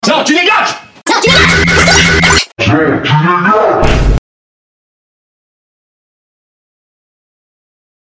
nan tu degage ultimate Meme Sound Effect